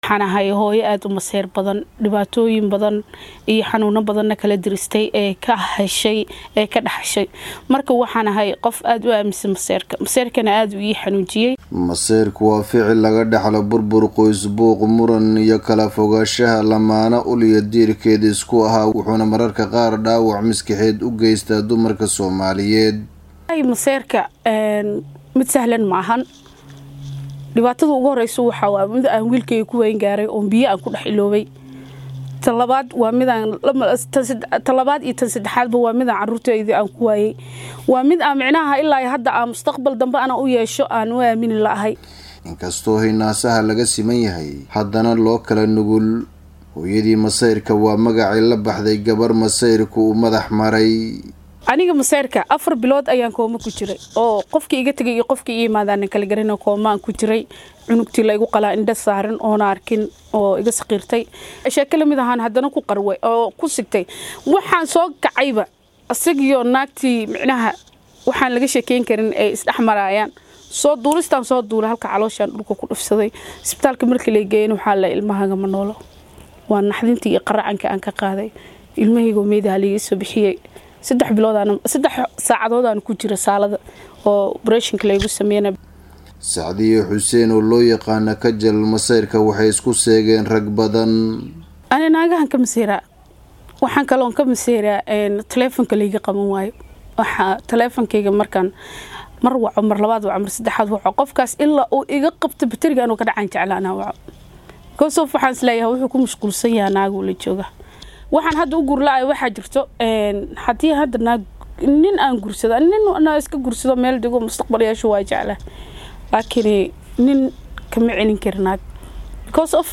warbixintan Dadaab nooga soo diray.